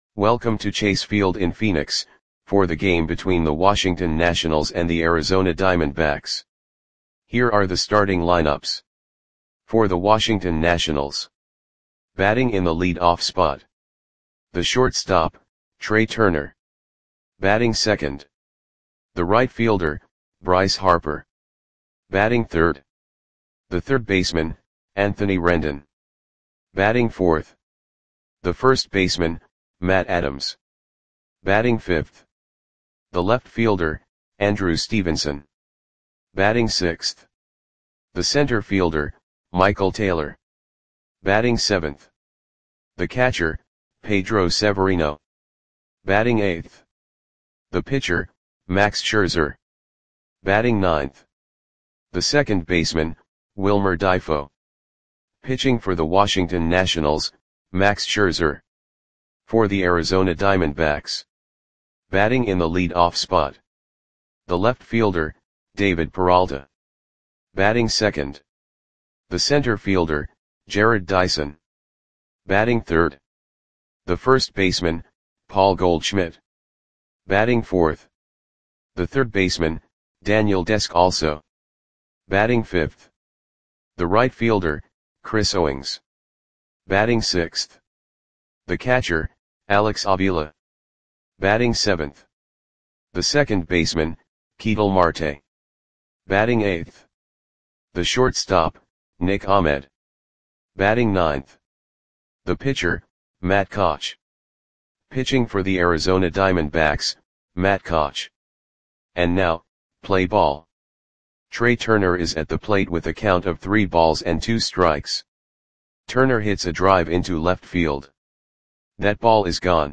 Audio Play-by-Play for Arizona Diamondbacks on May 11, 2018
Click the button below to listen to the audio play-by-play.